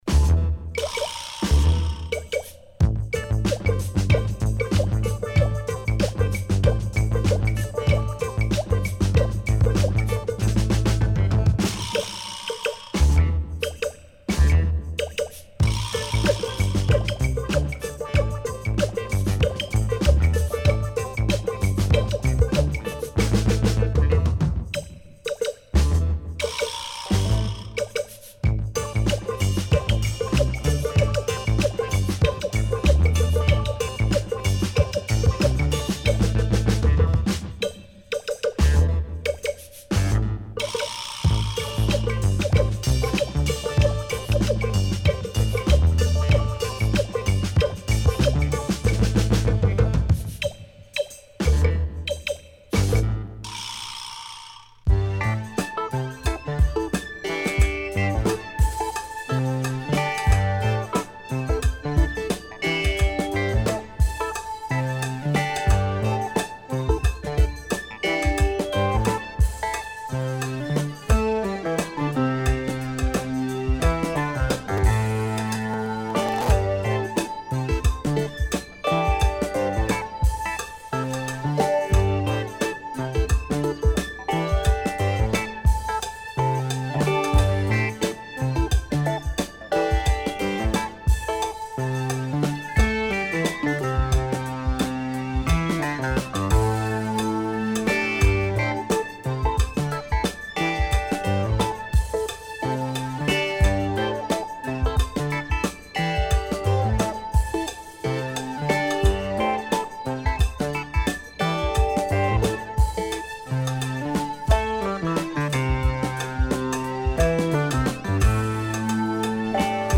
Heavy German funk cuts with plenty of terrific breaks
Mad samples ! Drums and synths galore : killer !